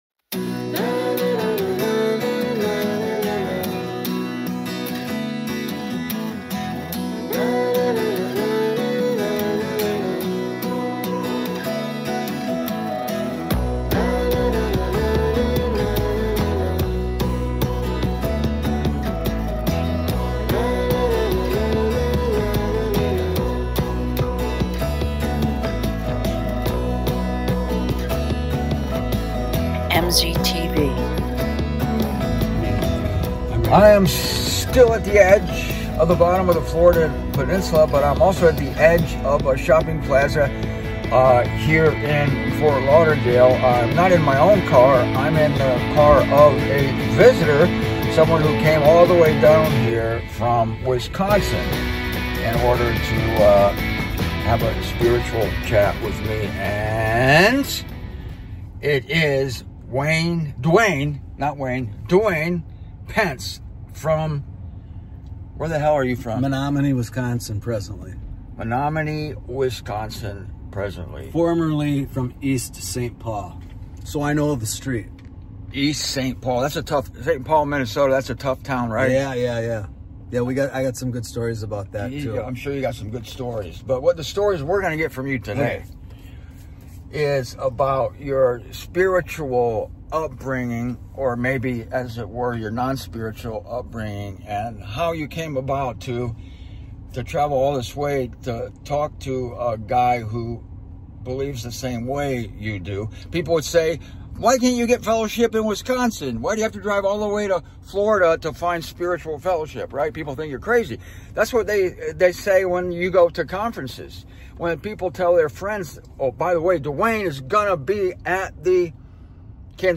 This may be the most free-wheeling, “out-of-control” interview I’ve ever conducted with a fellow member of the body of Christ.